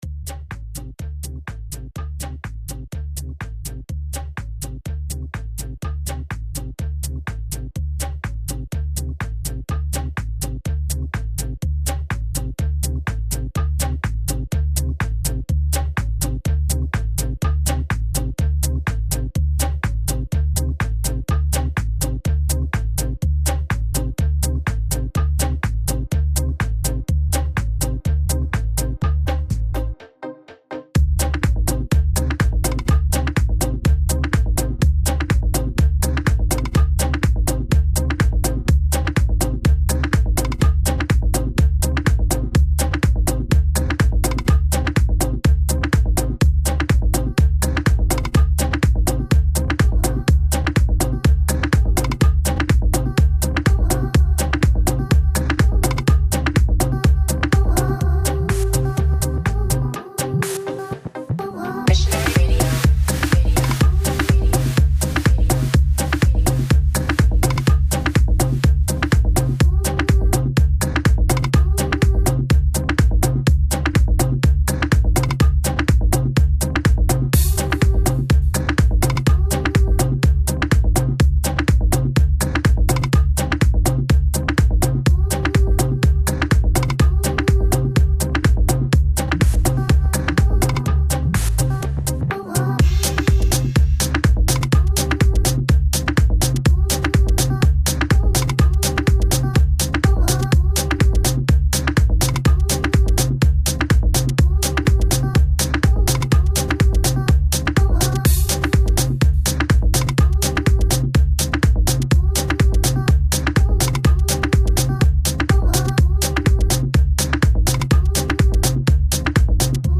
Online House, Tribal, Tech House, Trance and D&B.